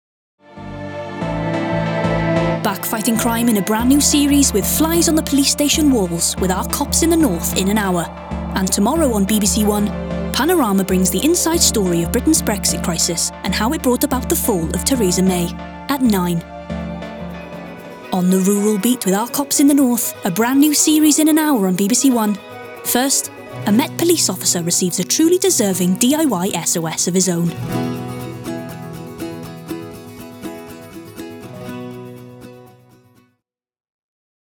Continuity Showreel
Non-binary
Friendly
Playful
Upbeat
Voice Next Door